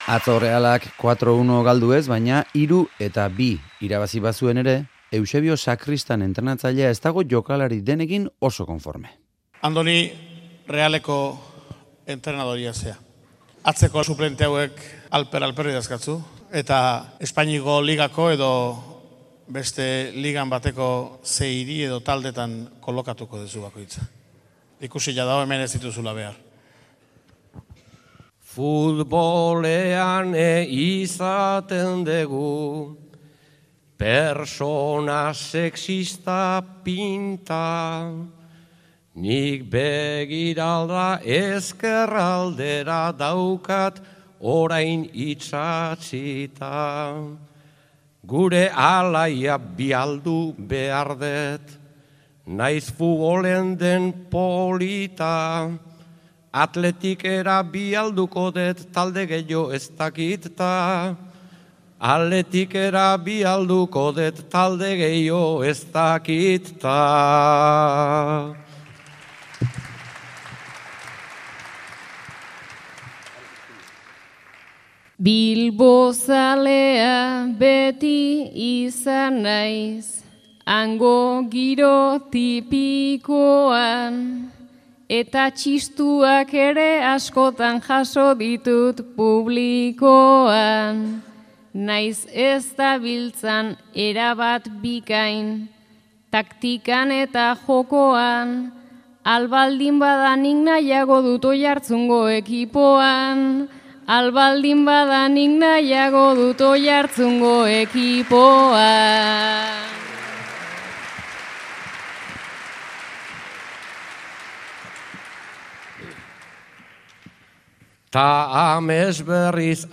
Sacristan apaizaren gaiari bertsotan: behar ez dituen ordezkoekin zer?